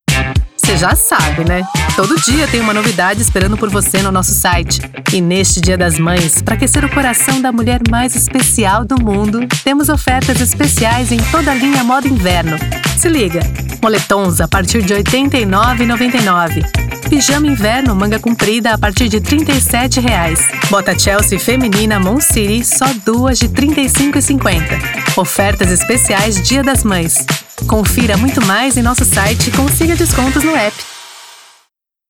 Feminino
Voz Jovem 00:32
Voz feminina, natural, adulta, sotaque neutro paulista.